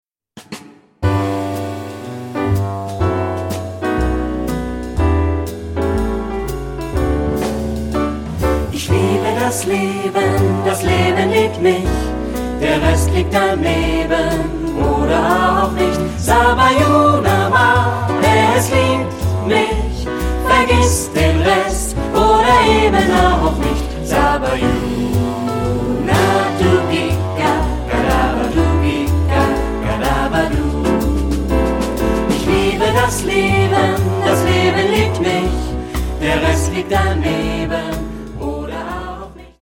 Ad libitum (3 voces ad libitum).
Canon. Coral jazz.
Carácter de la pieza : jazzeado ; rítmico ; ligero
Tonalidad : sol mayor